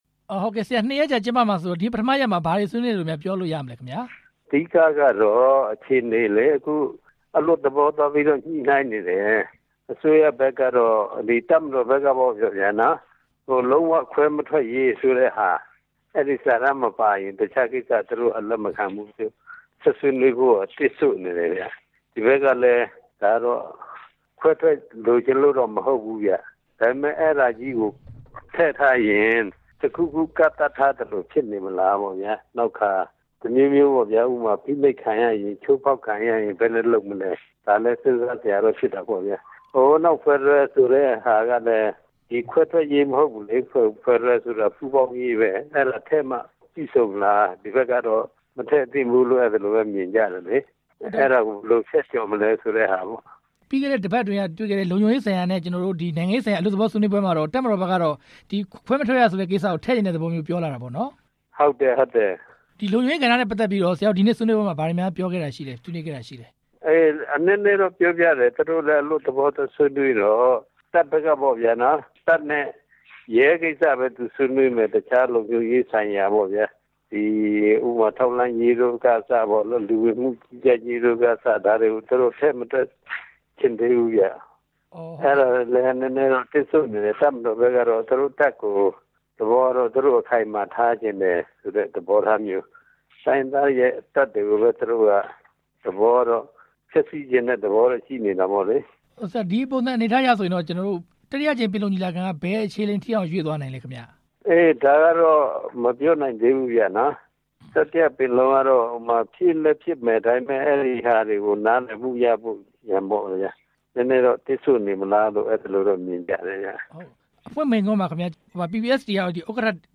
ချင်းမိုင်မှာ အပစ်ရပ်အဖွဲ့တွေ ဆွေးနွေတဲ့အကြောင်း မေးမြန်းချက်